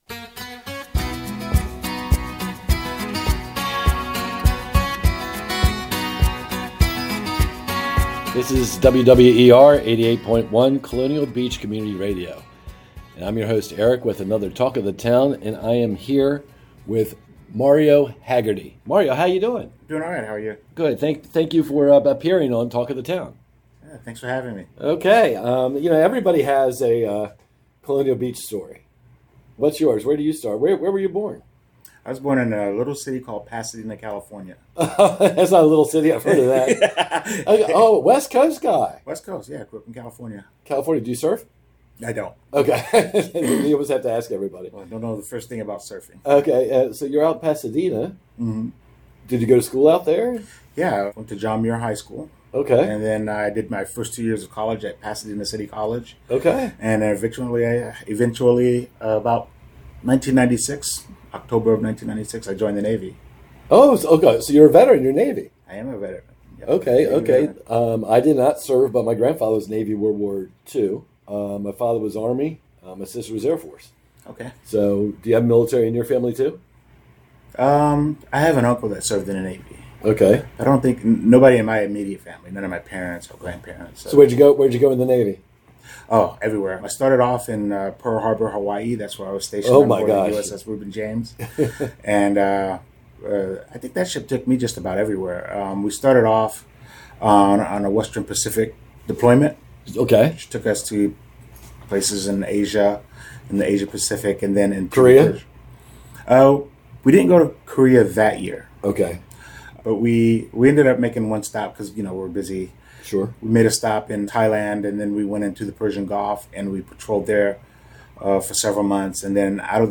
This interview on WWER